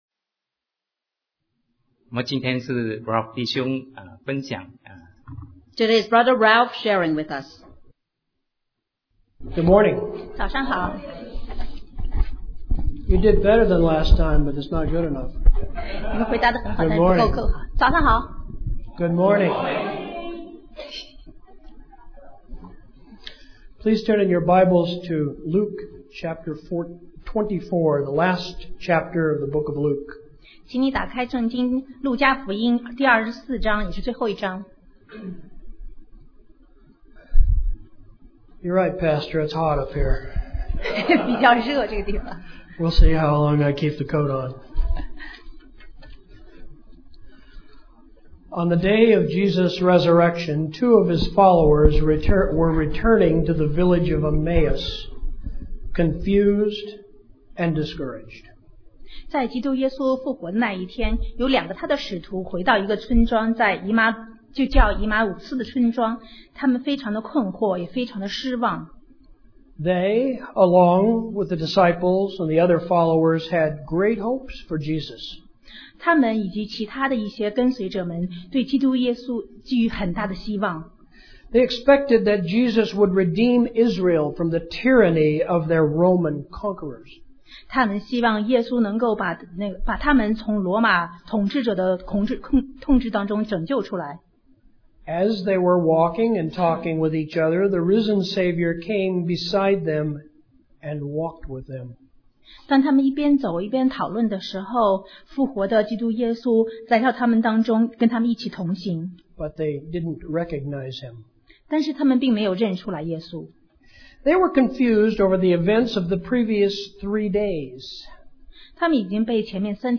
Sermon 2015-08-16 Jesus on the Road to Emmaus